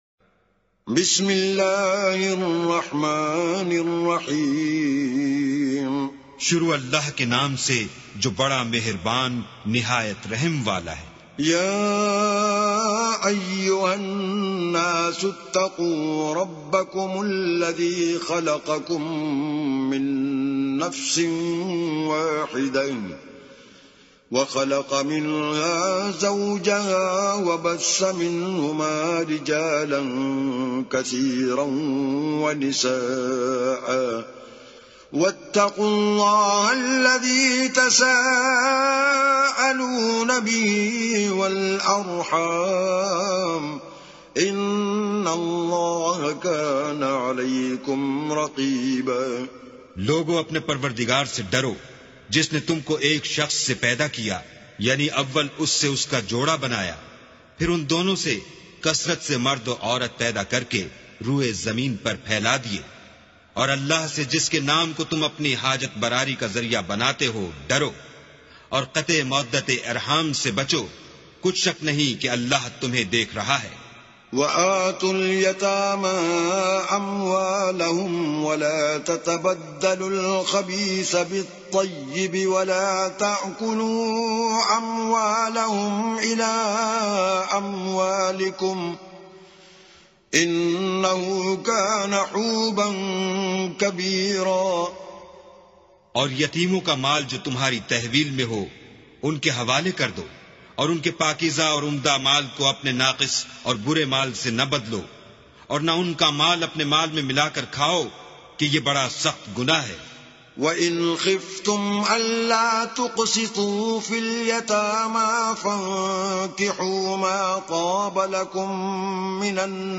arabic recitation